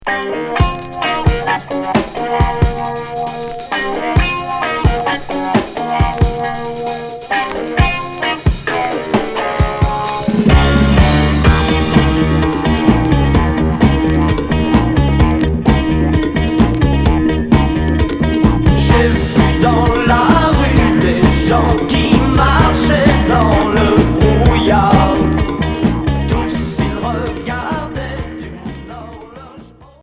Progressif